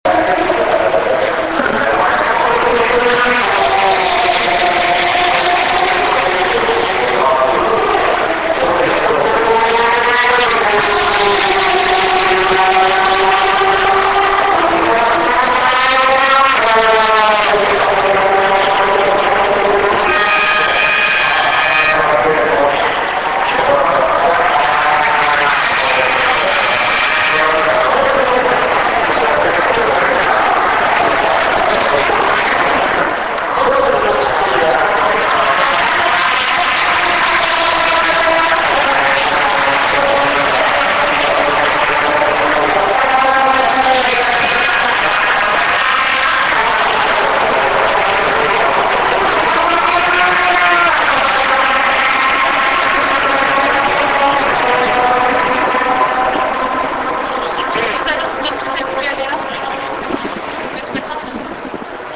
Hear Le Mans cars in action